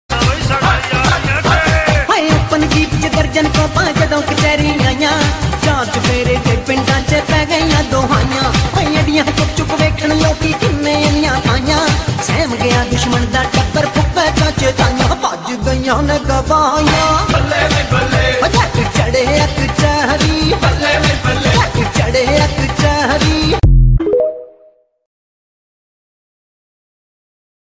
echo sound